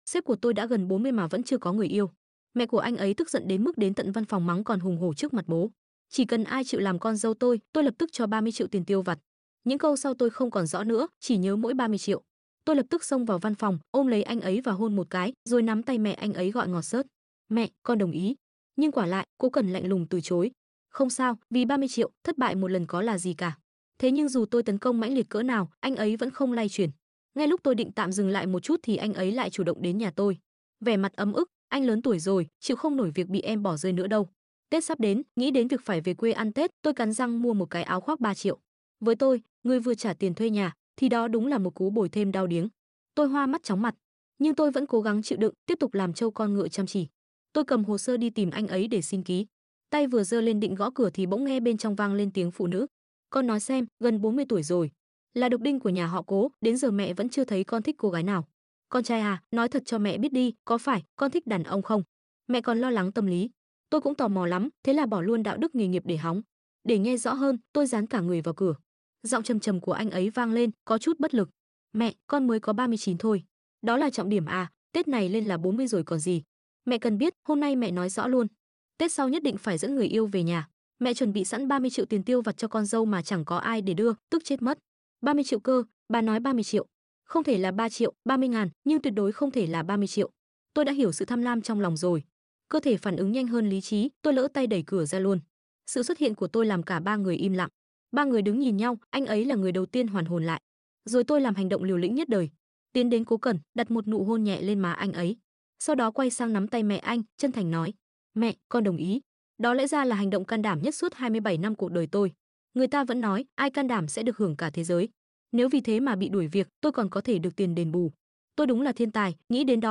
TRUYỆN AUDIO|| TỪ SÁU MƯƠI sound effects free download